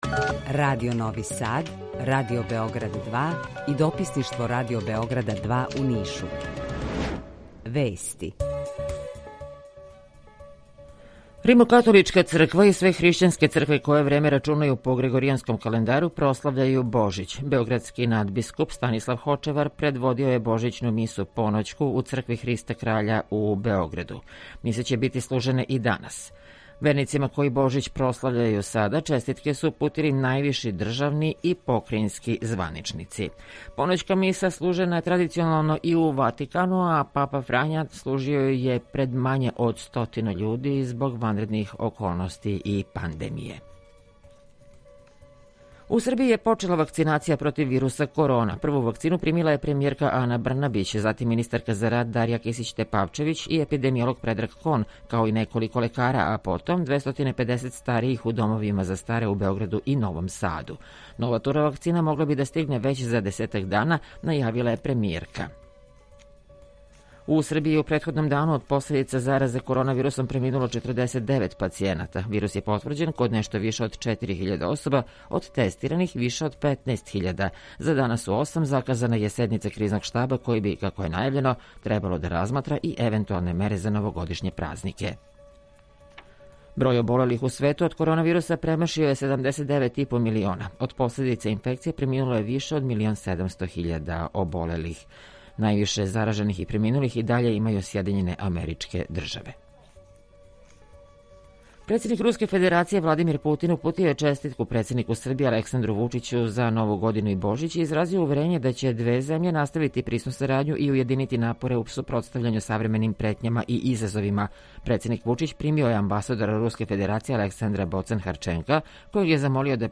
Три градоначелника из три студија - уживо из Београда, Ниша и Новог Сада
У два сата, ту је и добра музика, другачија у односу на остале радио-станице.